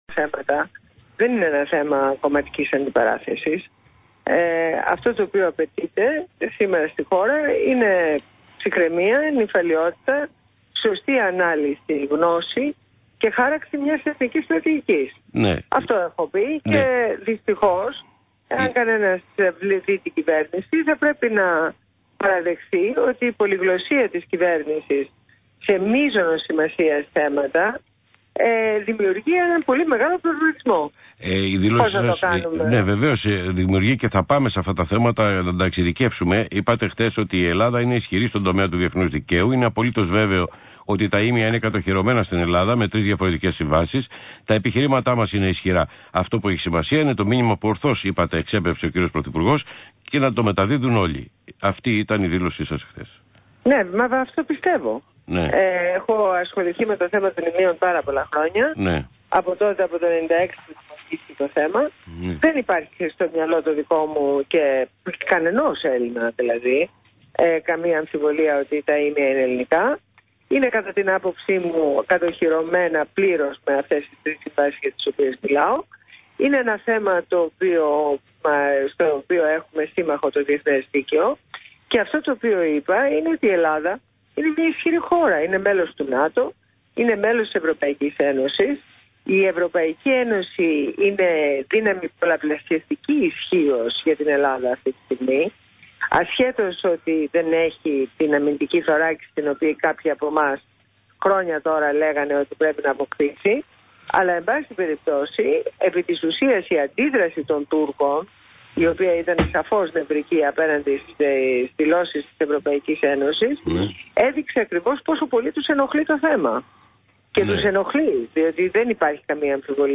Συνέντευξη στο ραδιόφωνο ΣΚΑΪ 100.3